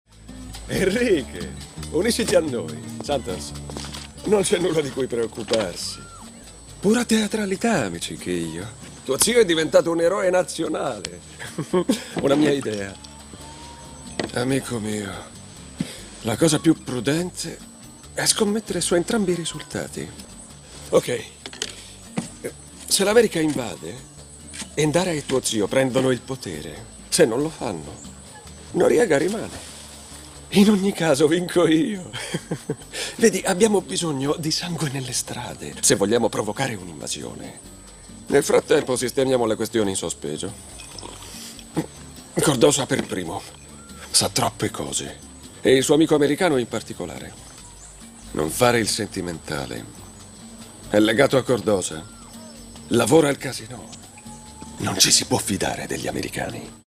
vocemare.mp3